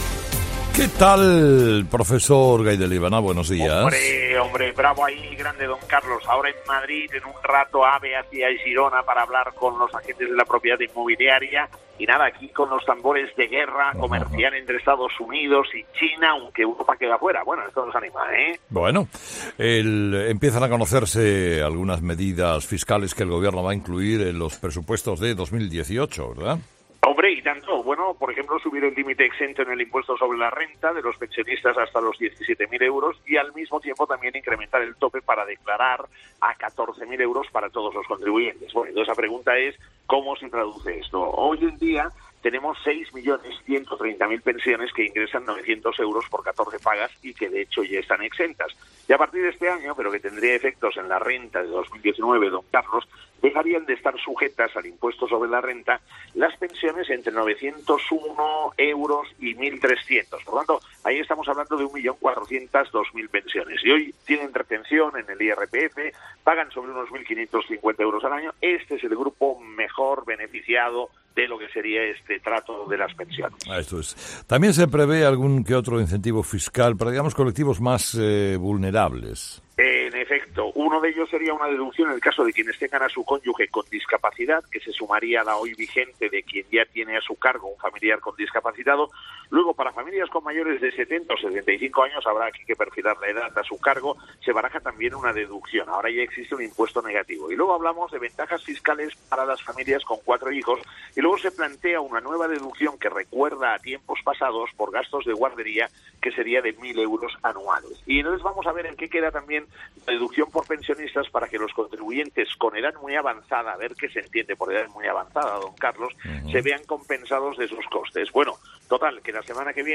Escucha ahora 'Gay de Liébana: “Medidas de los Presupuestos de 2018: subir el límite exento en el IRPF de los pensionistas”', emitido el viernes 23 de marzo de 2018, en ‘Herrera en COPE’